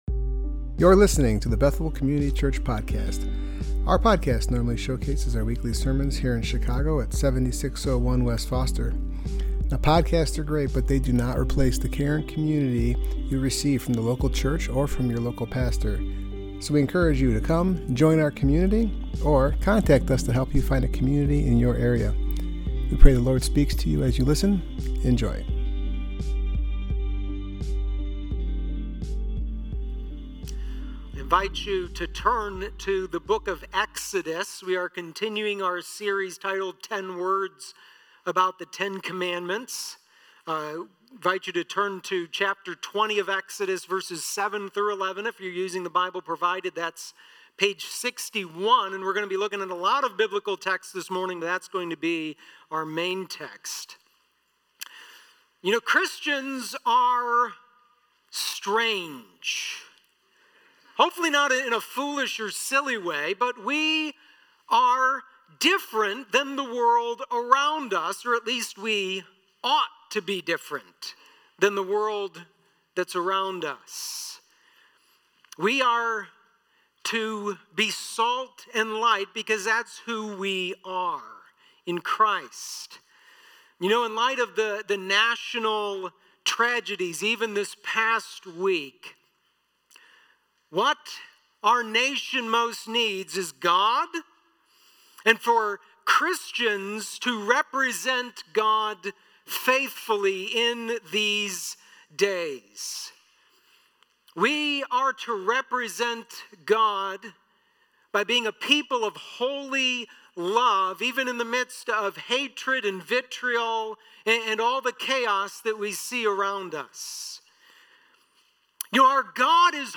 Passage: Exodus 20:7-11 Service Type: Worship Gathering